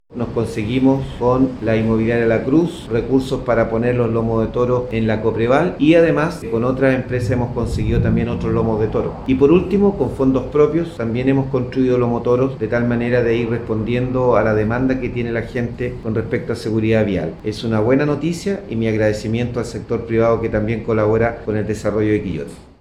03-ALCALDE-Lomos-de-Toro.mp3